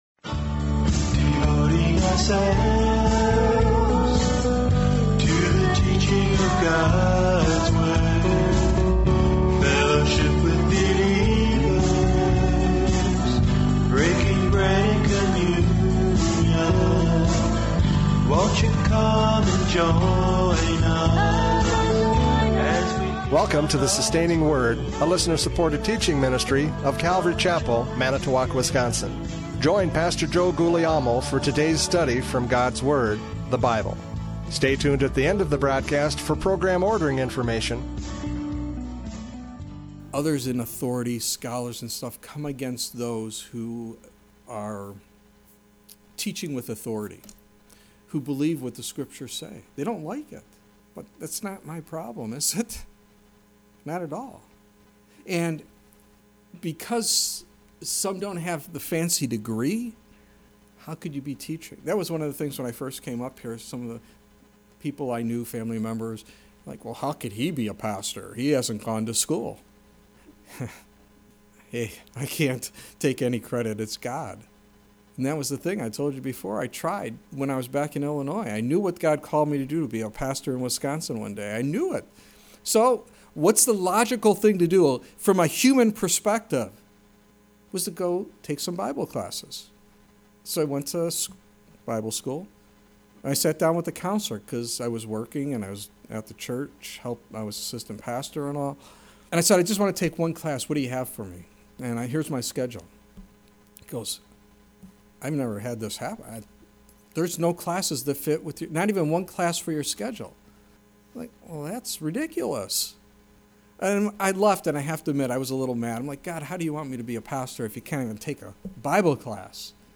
John 7:14-36 Service Type: Radio Programs « John 7:14-36 Debating Jesus!